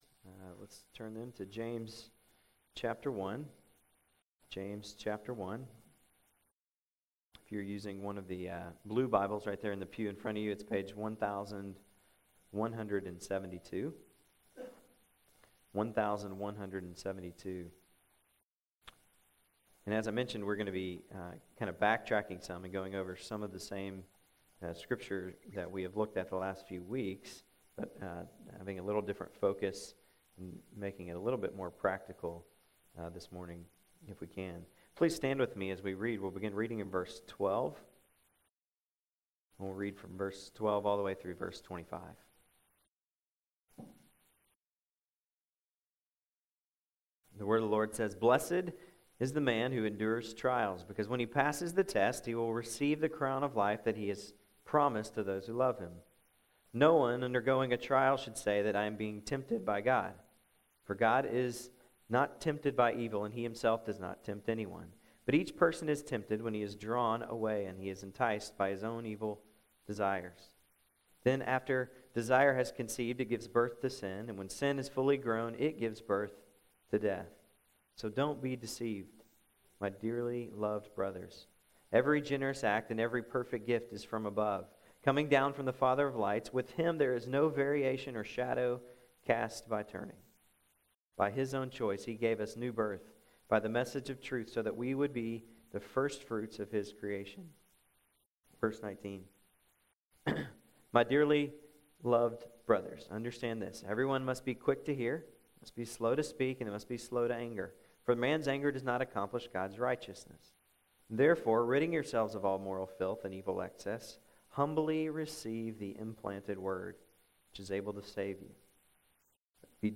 September 4, 2011 AM Worship | Vine Street Baptist Church